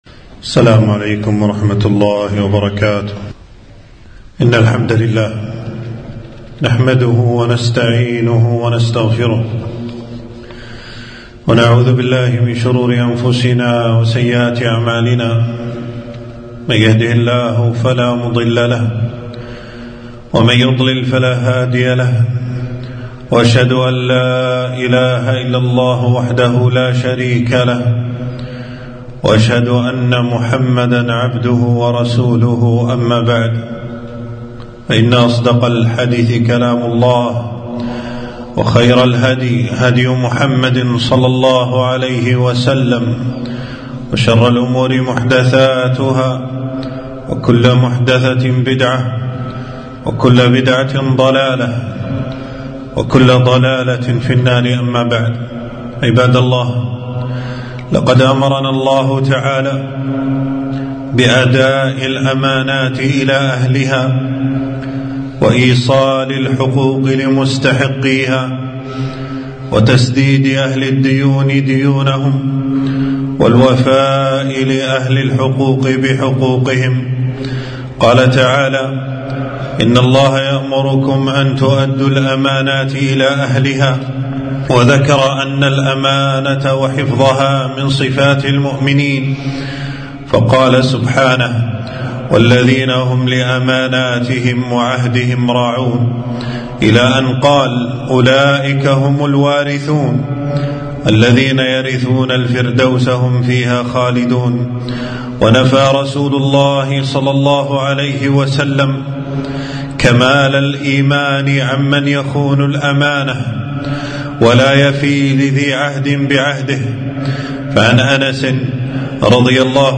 خطبة - ما أعظمها من أمانة (قصص نبوية في الأمانة)